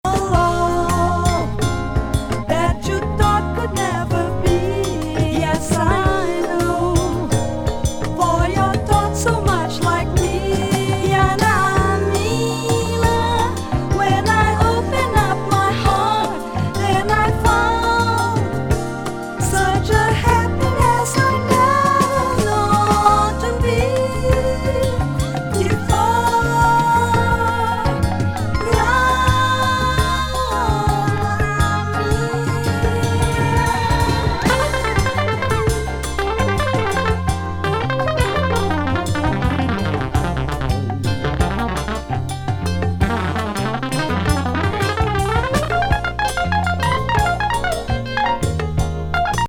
エレクトリック・メロウ・フュージョン!